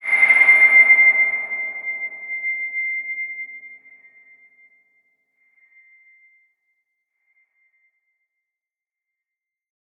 X_BasicBells-C5-ff.wav